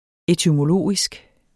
Udtale [ etymoˈloˀisg ]